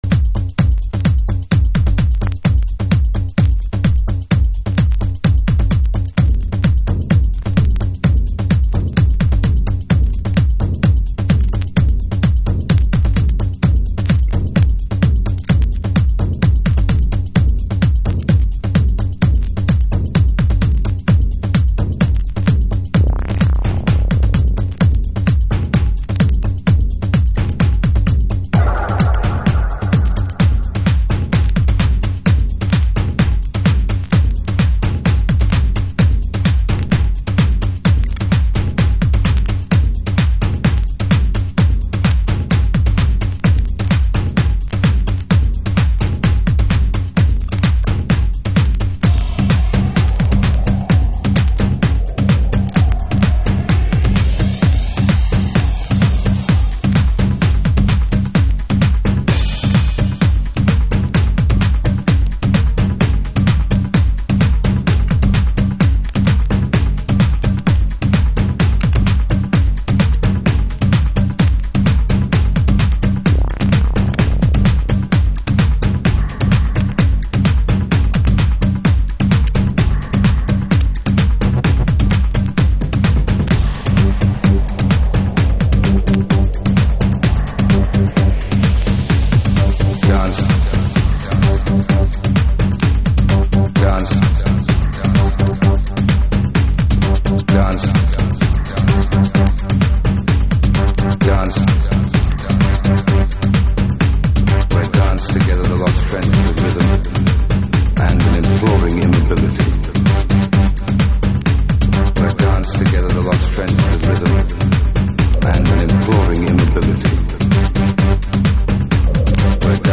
この盤、トライバル要素も良い塩梅。
Early House / 90's Techno